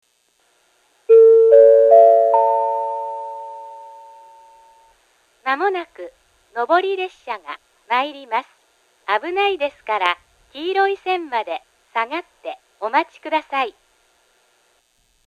２番線上り接近放送